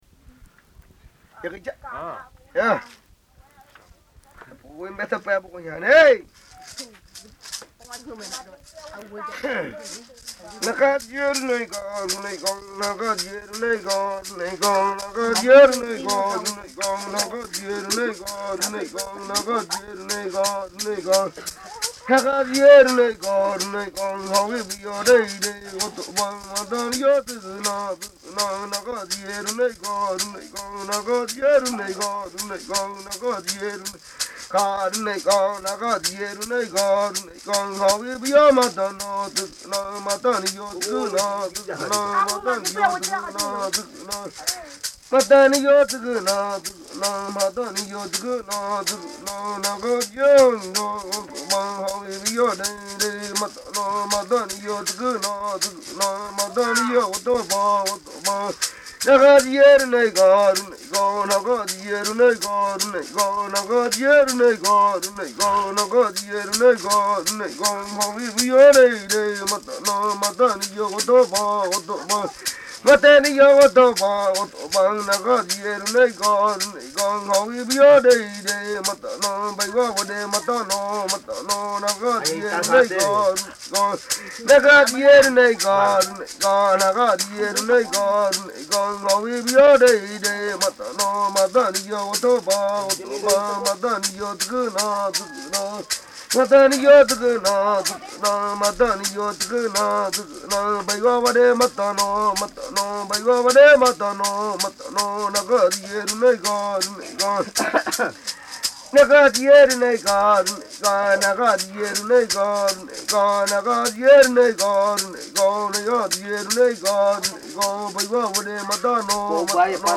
La presente grabación tiene esta nota: "Yopo + canto + danza."